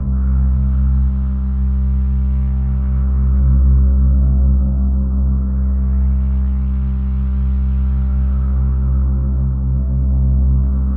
Tag: 175 bpm Drum And Bass Loops Pad Loops 1.85 MB wav Key : C